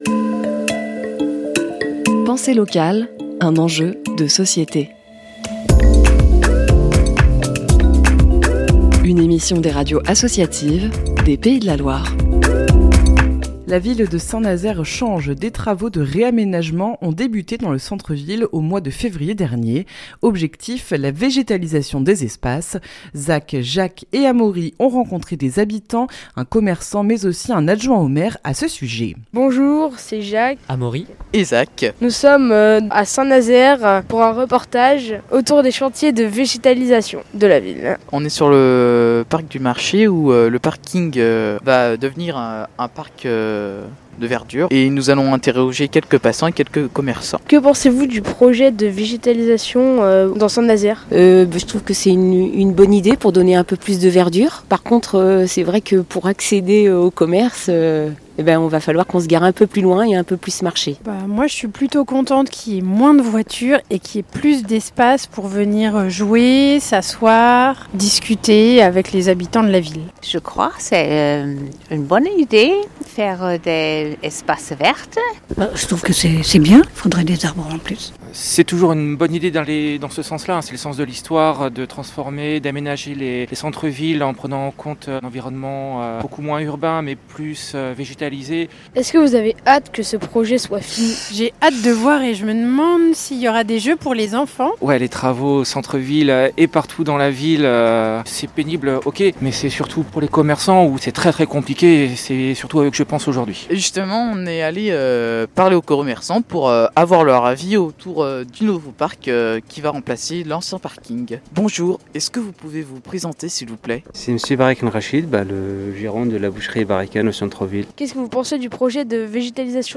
Reportage-vegetalisation-St-Nazaire-LA-TRIBU-V3-MP3.mp3